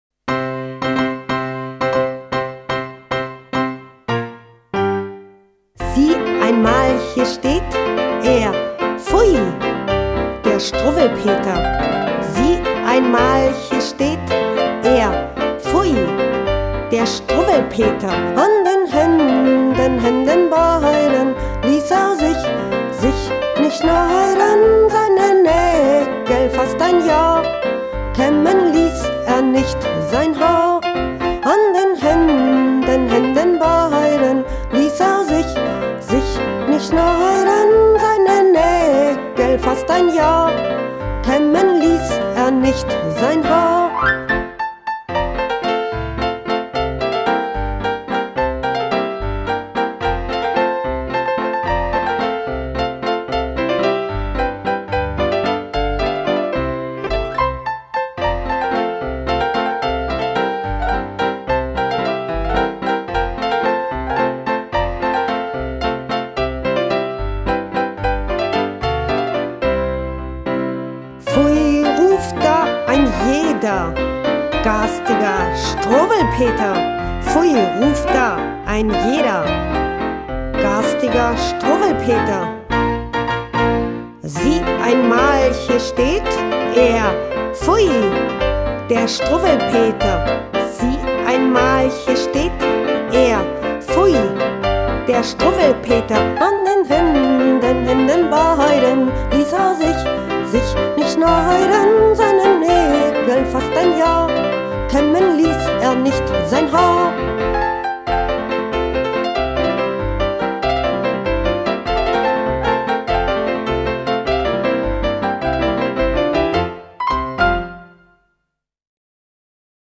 Gesang und Sprache